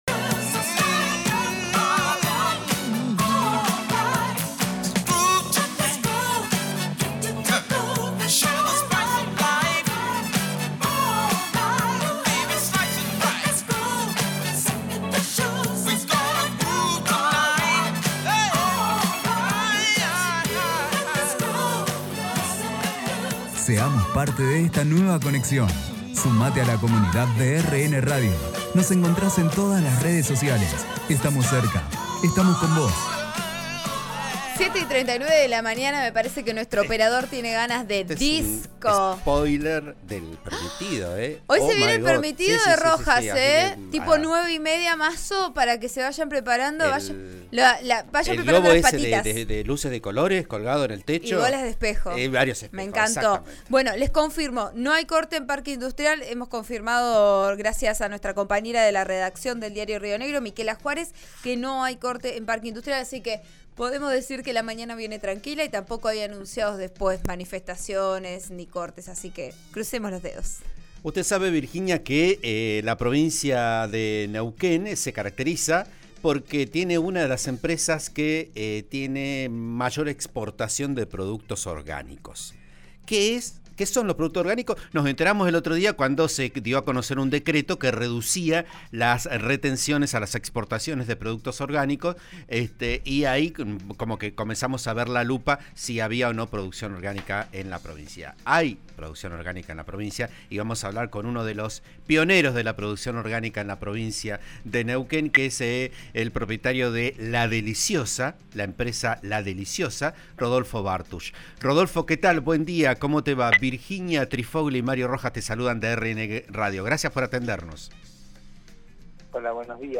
La empresa líder de exportación orgánica de la región habló en RN RADIO sobre los detalles este tipo de producción.